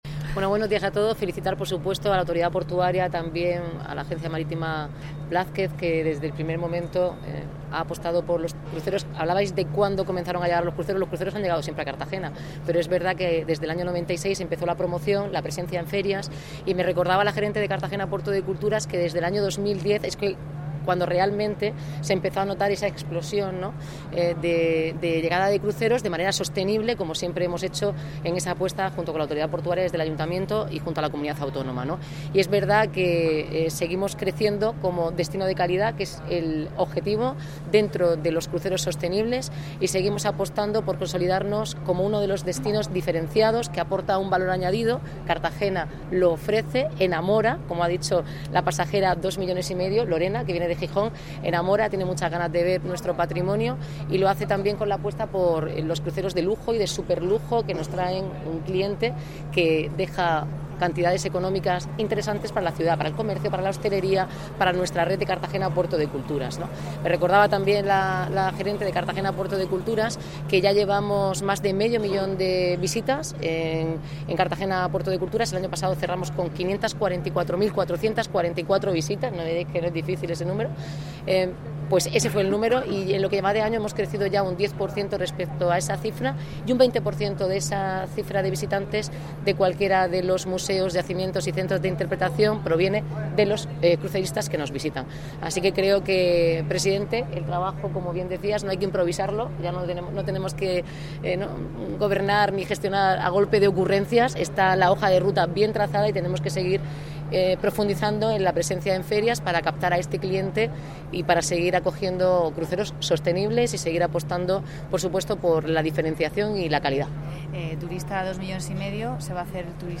Declaraciones de la alcaldesa Noelia Arroyo